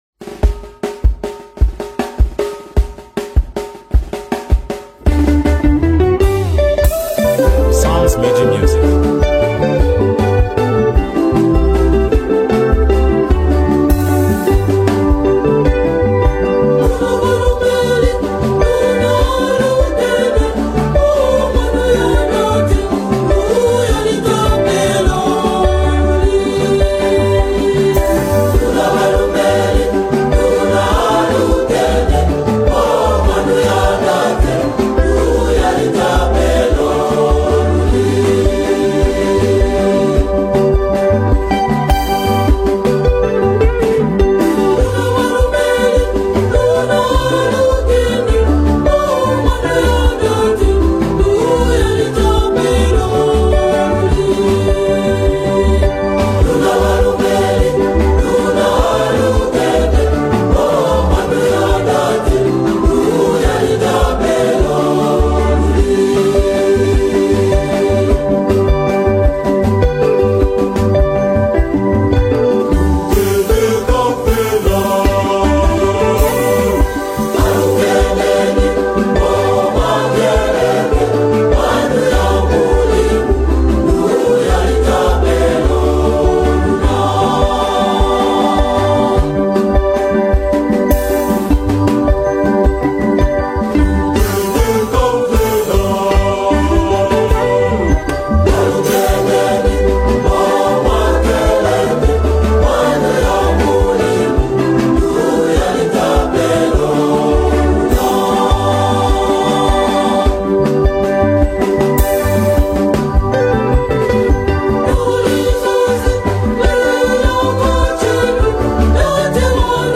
CATHOLIC WORSHIP REFLECTION